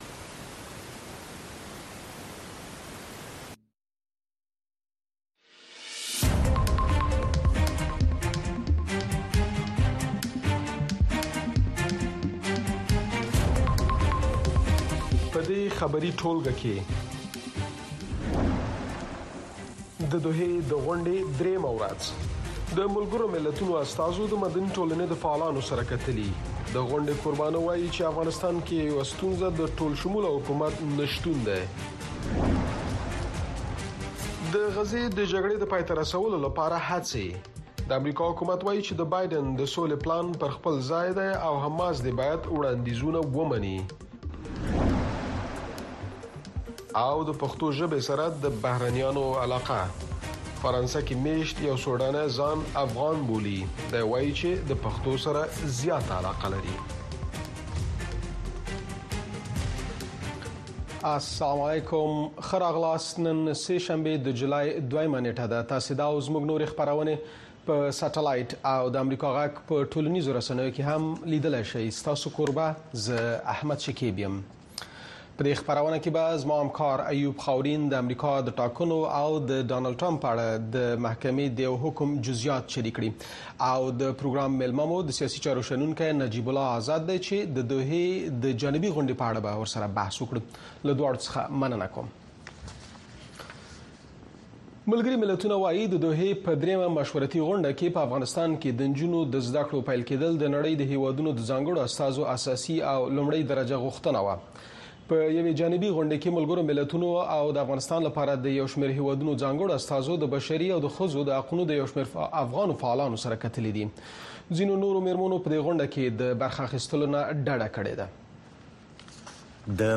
د اشنا خبري خپرونه